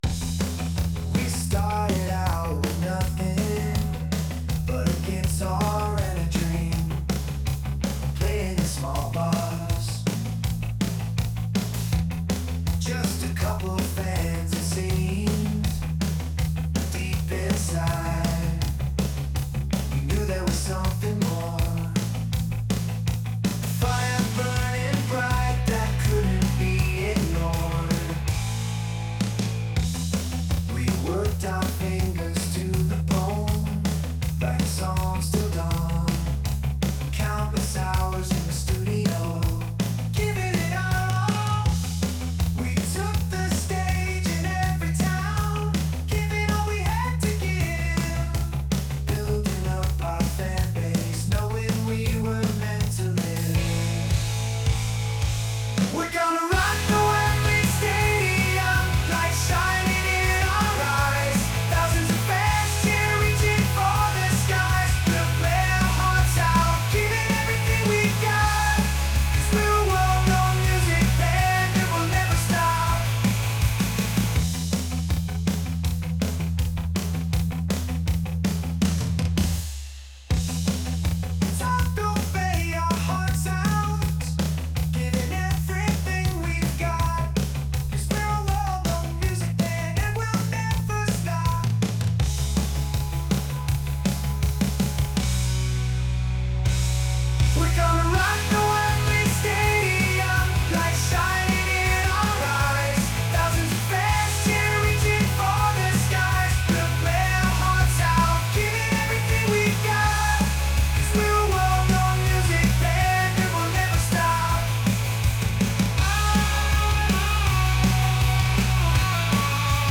Electric Bass, Drums, Electric Guitars, Chorus
Genre: Blues and Rock